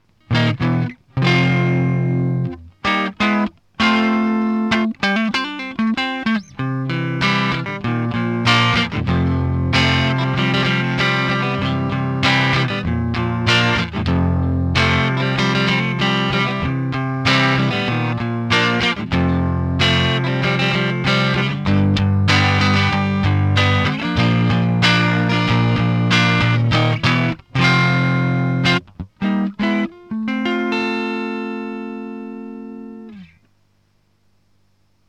quelques samples pour te faire une idée (sortie line avec un cut des aigus pour simuler rapidement un cab)
ef86_drive_light_manche.ogg